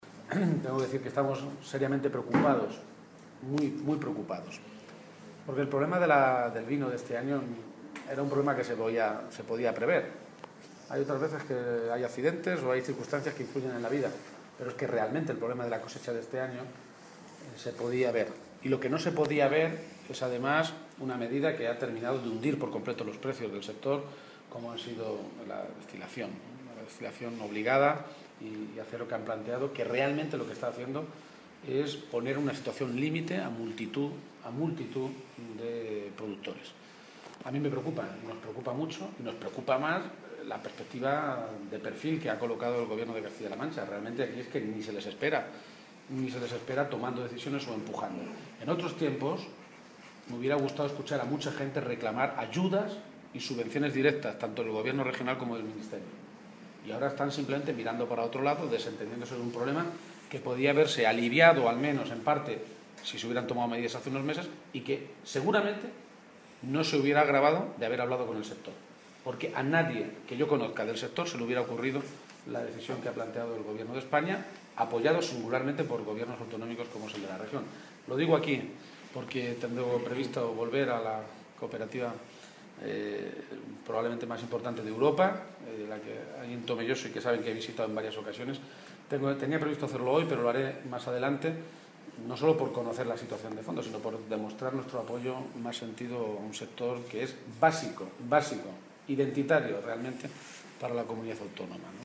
García-Page se pronunciaba de esta manera esta tarde, en Tomelloso, en unas declaraciones a los medios de comunicación en las que hacía referencia al hecho de que esta campaña de vendimia haya comenzado con los precios más bajos de la uva de los últimos 25 años.